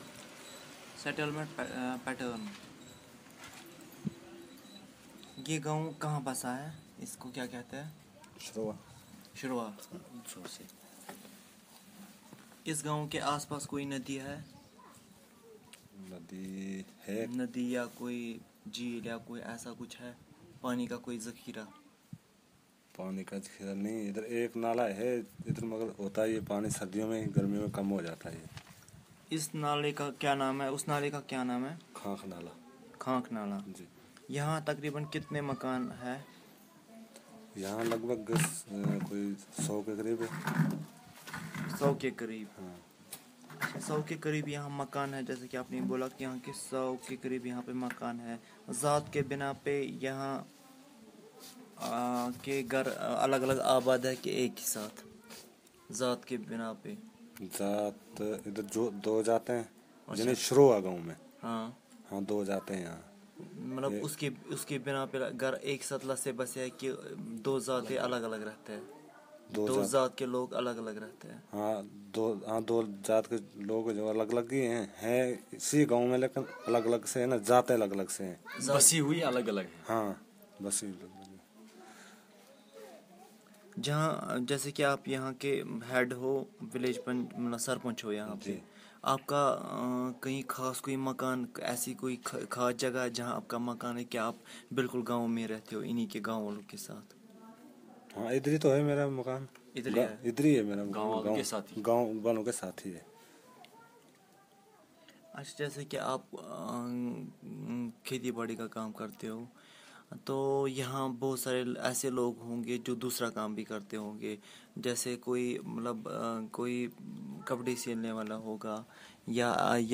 Narrative about the surroundings of the village